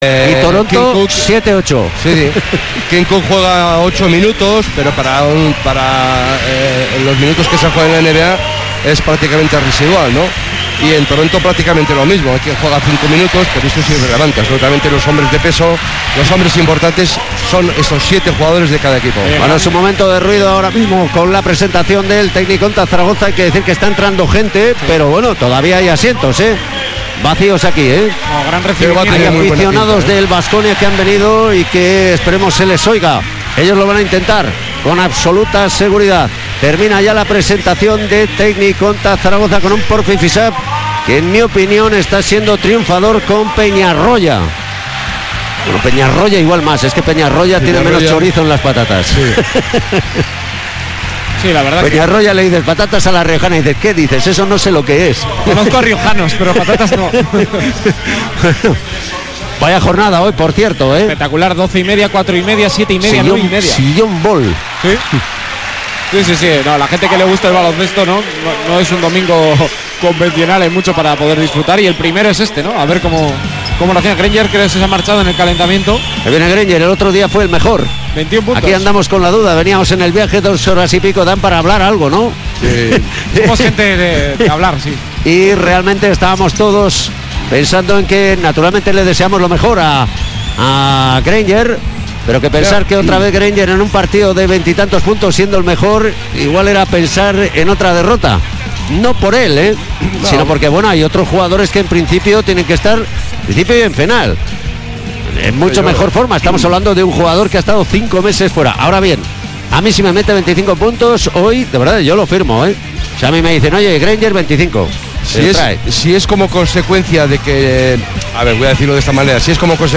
Zaragoza-Baskonia partido 2 cuartos de final liga ACB 2018-19 retransmisión Radio Vitoria (solo primera parte)